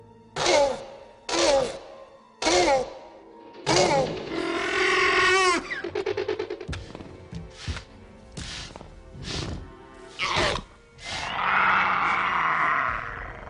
Effets Sonores